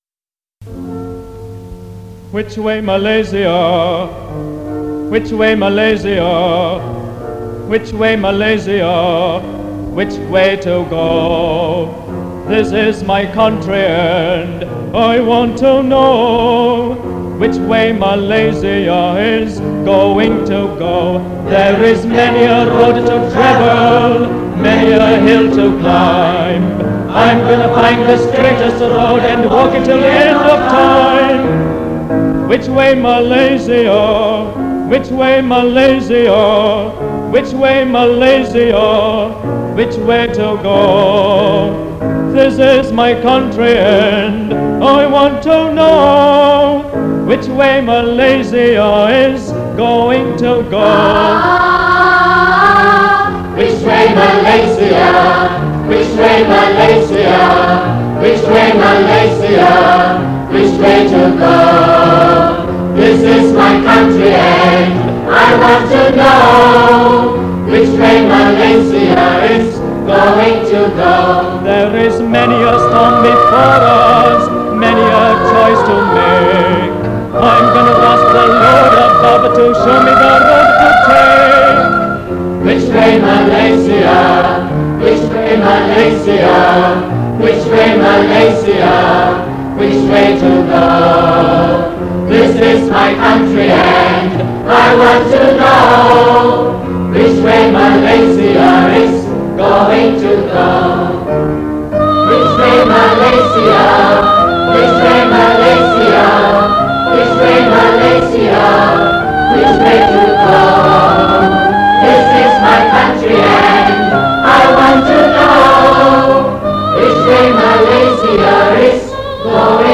This is the final part of the Singout - a choral festival hosted by Methodist Girls' School (MGS) back in 1968. Together with the students of MGS, a few other schools also joined in to make up a 80-member strong choir.